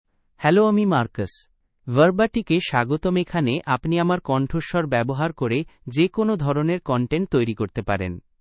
Marcus — Male Bengali AI voice
Marcus is a male AI voice for Bengali (India).
Voice sample
Listen to Marcus's male Bengali voice.
Male
Marcus delivers clear pronunciation with authentic India Bengali intonation, making your content sound professionally produced.